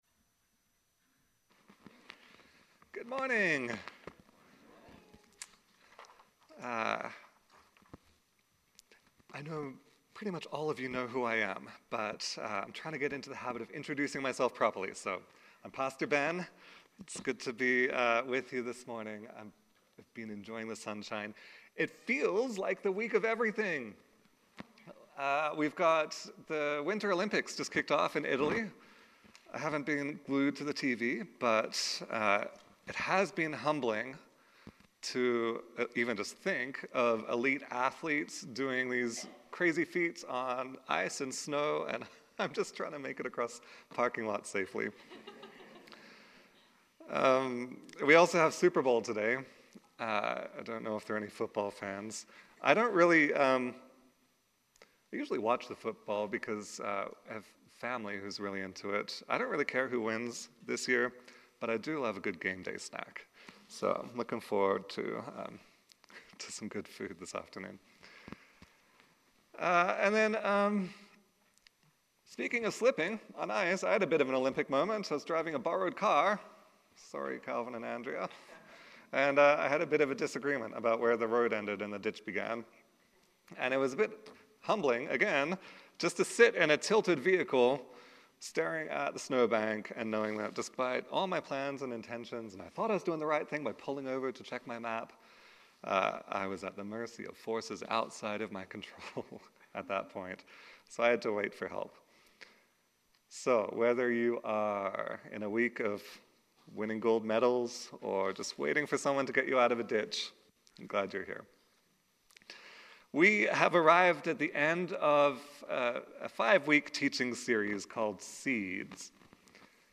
Sermons | Clairmont Community Church